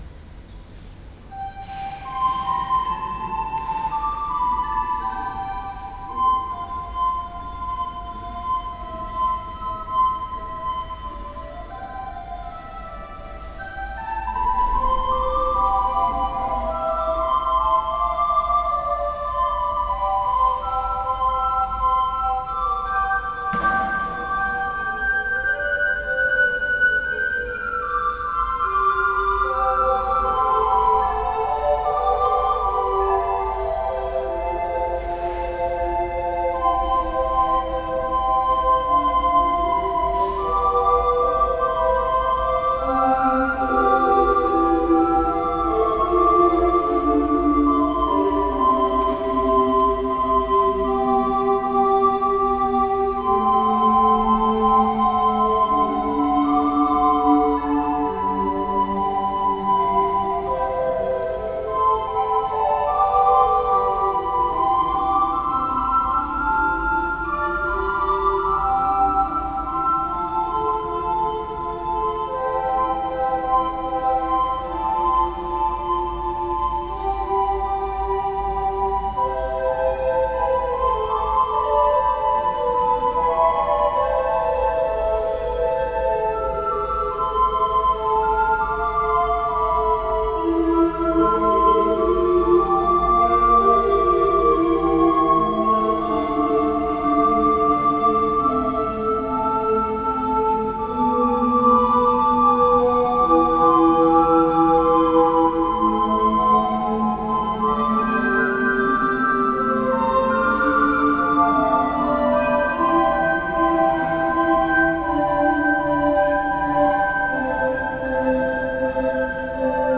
Hier können Sie gern einige Klangeindrücke unseres Ensembles gewinnen: